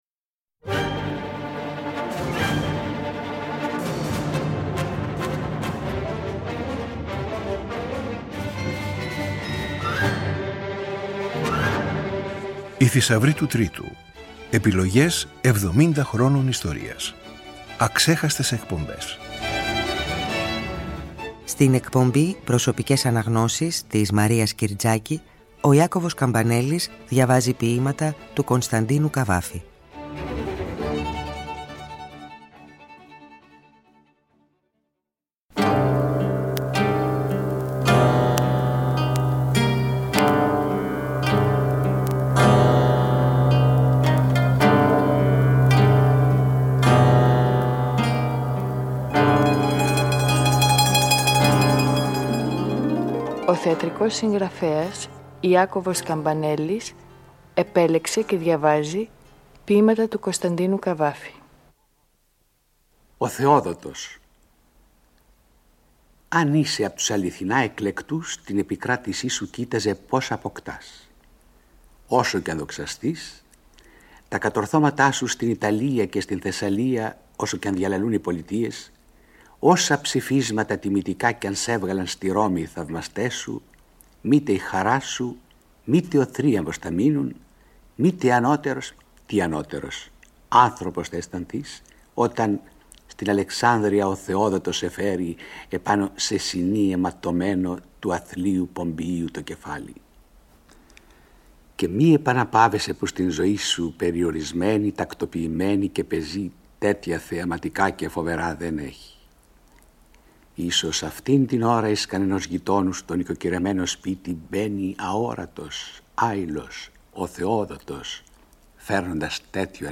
18η Εκπομπή: Κύκλος Αξέχαστες Εκπομπές, Ο Ιάκωβος Καμπανέλλης διαβάζει Κ. Π. Καβάφη | Τετάρτη 10 Ιουλίου 2024, 18:00
Το Τρίτο Πρόγραμμα στο πλαίσιο του εορτασμού των 70 χρόνων λειτουργίας του συνεχίζει τη σειρά μεταδόσεων ΟΙ ΘΗΣΑΥΡΟΙ ΤΟΥ ΤΡΙΤΟΥ, δίνοντας μία καλή αφορμή για να θυμηθούν οι παλαιότεροι και να ακούσουν για πρώτη φορά οι νεότεροι μερικά από τα διαμάντια του αρχειακού υλικού.
Στην εκπομπή της Τετάρτης 10 Ιουλίου ακούμε ακόμα μία μοναδική ραδιοφωνική «συνάντηση» κορυφαίων του Ελληνικού Λόγου και της Τέχνης: ο Ιάκωβος Καμπανέλλης διαβάζει Κ. Π. Καβάφη.